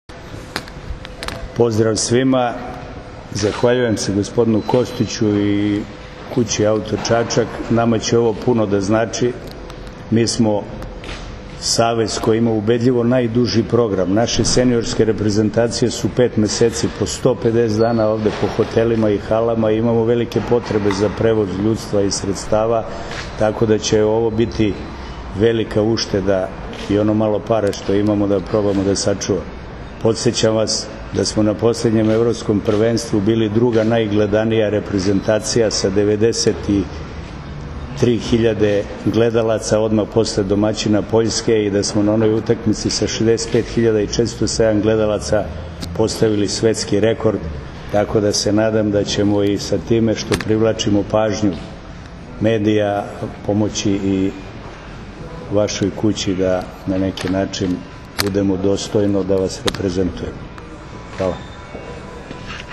U prostorijama kompanije “Auto Čačak” na Novom Beogradu, danas je svečano potpisan Ugovor o saradnji između kompanije “Auto Čačak” i Odbojkaškog Saveza Srbije.
IZJAVA